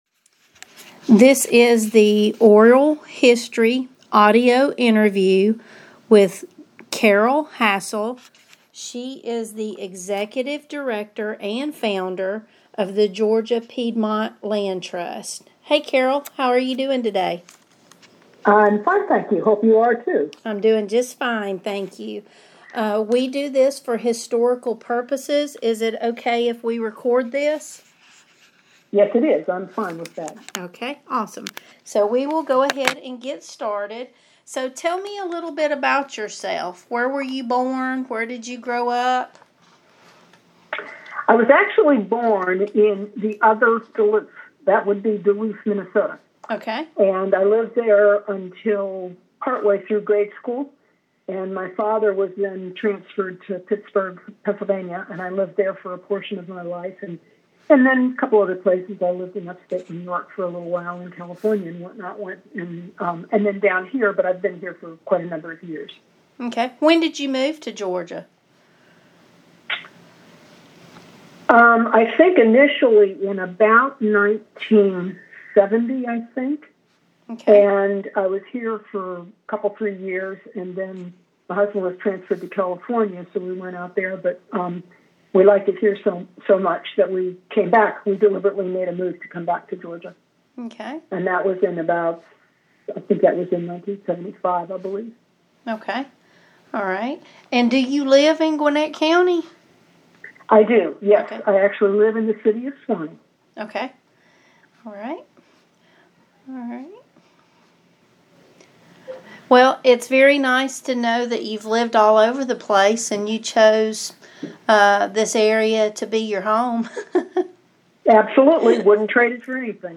Georgia Piedmont Land Trust Audio Interview Subject Oral histories Sugar Hill
via telephone